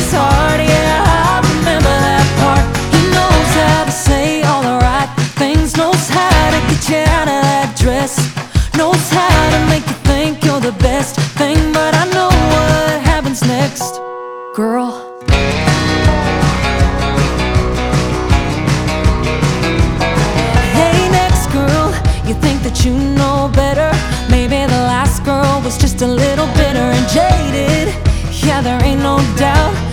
• Country